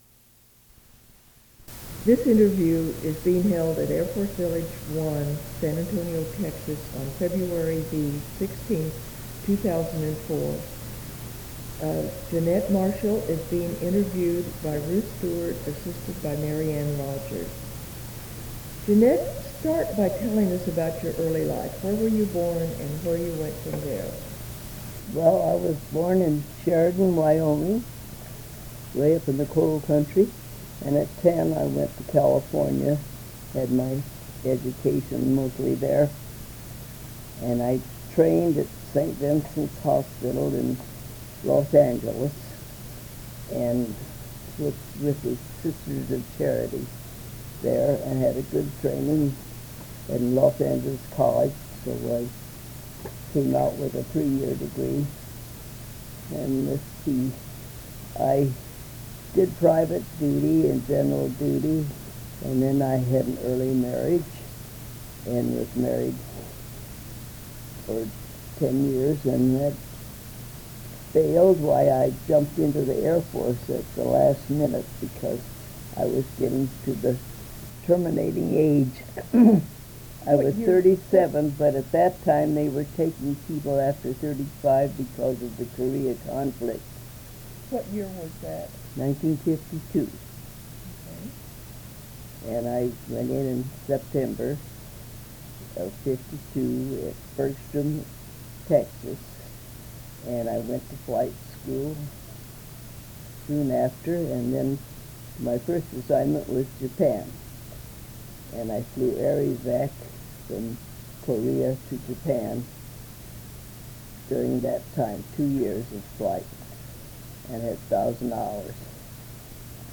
Oral History Project
Sound recordings Interviews